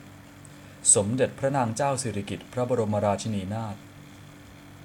1. ^ Thai: สิริกิติ์, pronounced [sì.rì.kìt]